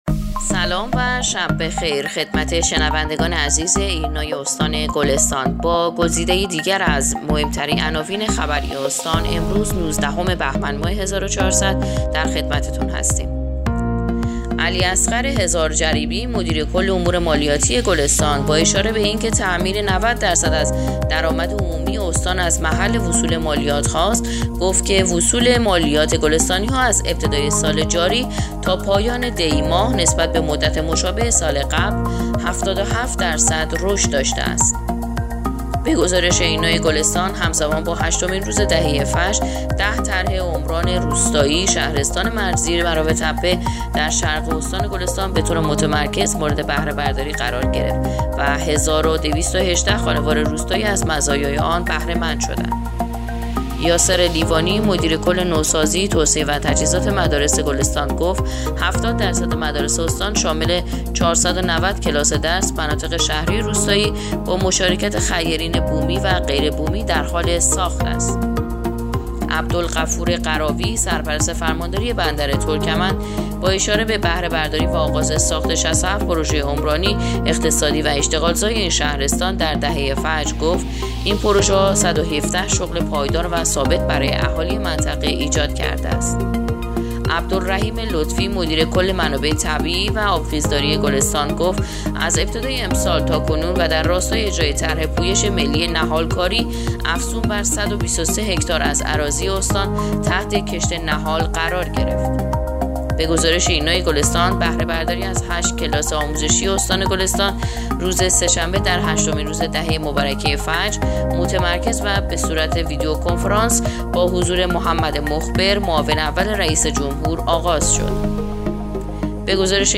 پادکست/ اخبار شبانگاهی نوزدهم بهمن ماه ایرنا گلستان